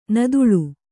♪ naduḷu